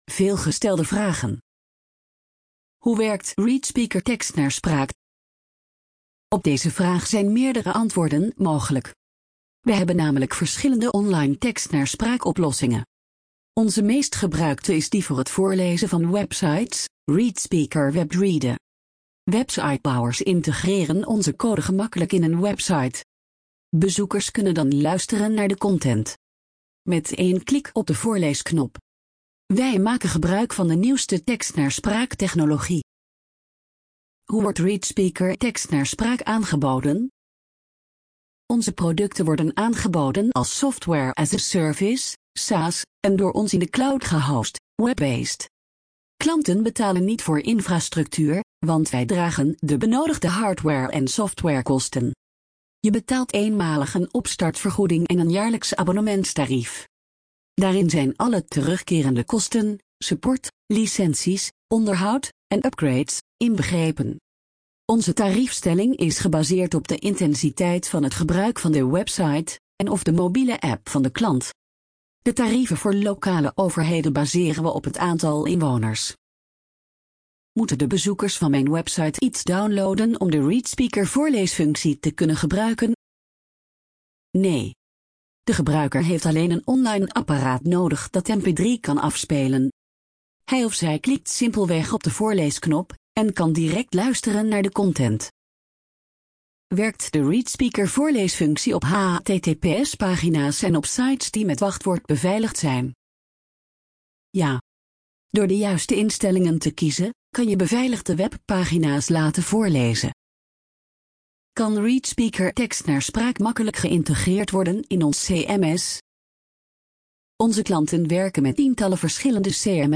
FAQ_NL - TTS.mp3